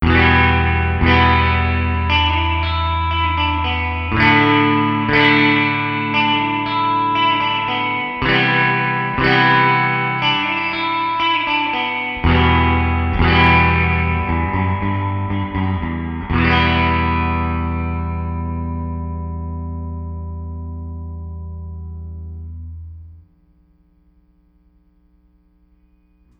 Here’s the same close-mic’d guitar performance you heard earlier; this time, though, I have turned on the reverb knob on the amp.
Our brain is telling us that the guitar is now in some sort of space.
Well, literally, it’s the space and the springs inside this little 9-inch steel can in the back of the guitar amp.
GtrAmpSpringReverb.wav